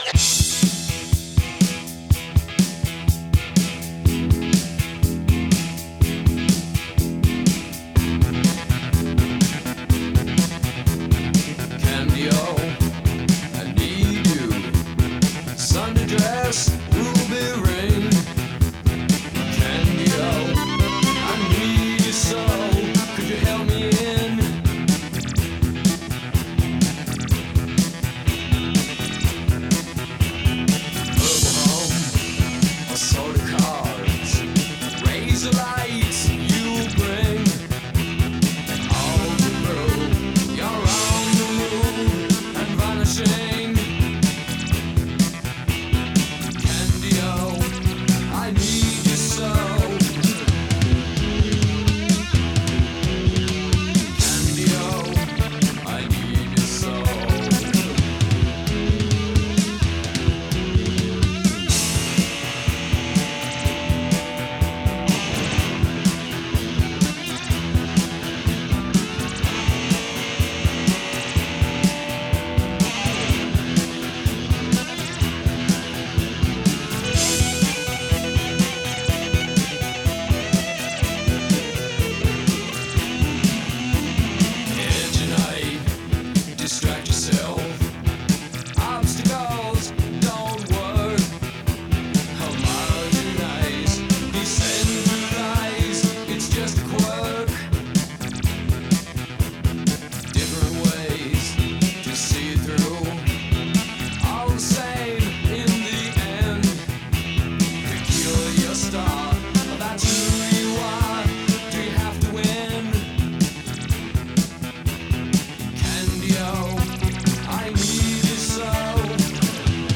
new wave pop rock
perfect guitar pop really.